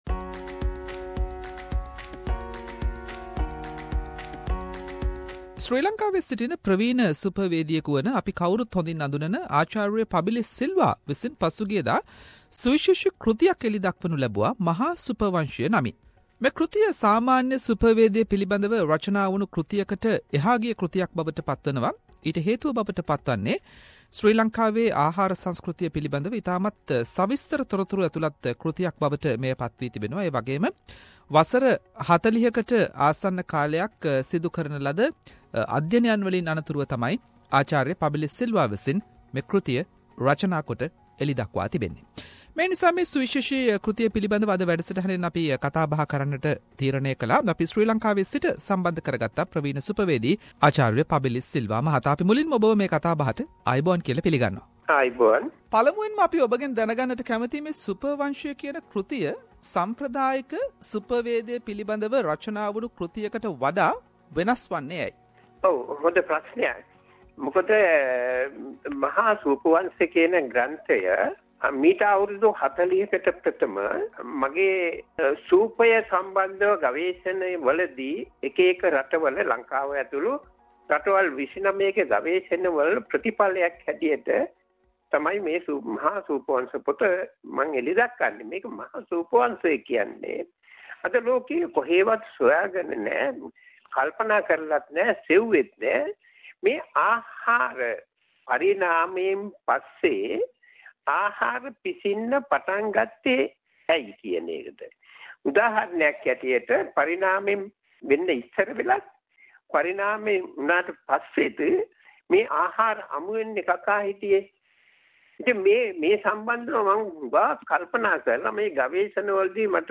SBS Sinhalese interview